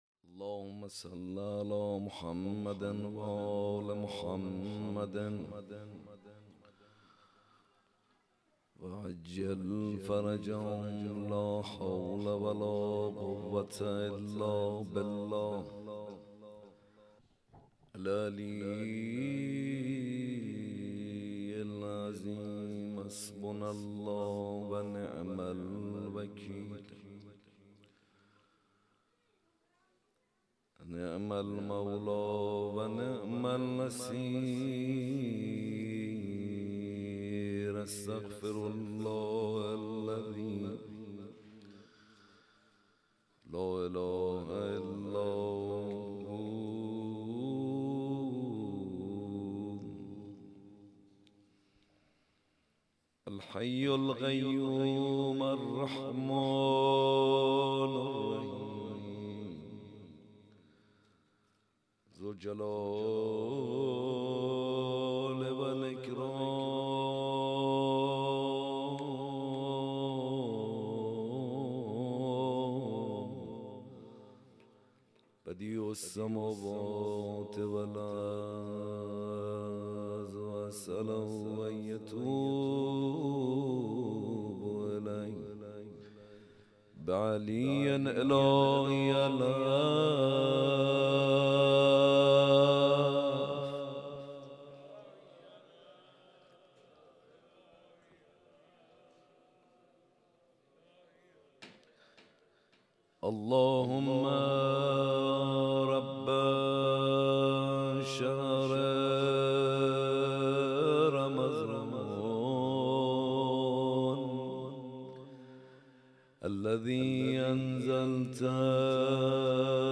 حسینیه کربلا
شب 19 رمضان